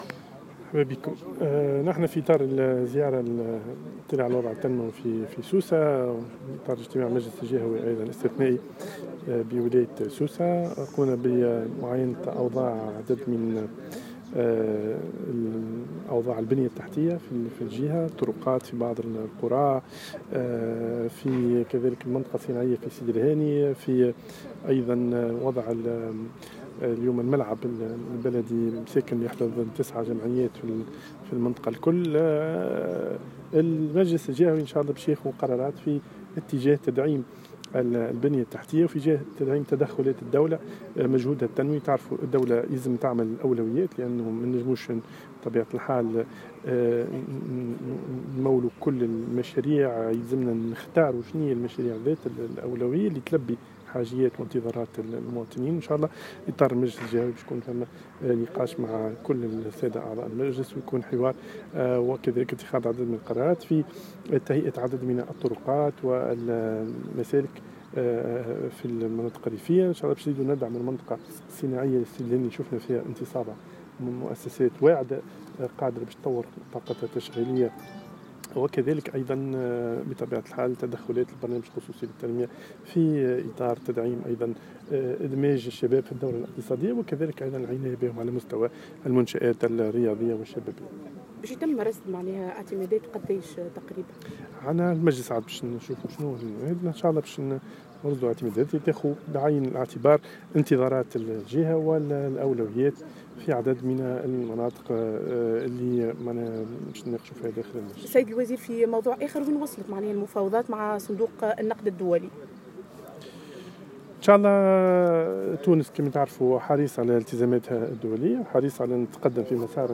و أكد لوزير في تصريح لراديو أرام أف أم أنه و على ضوء هذه الزيارة سيتم إتخاذ عديد القرارات لتدعيم البنية التحتية بالجهة و تعزيز تدخلات الدولة في المجال التنموي و تطوير الطاقة التشغيلية و إدماج الشباب في الدورة الإقتصادية.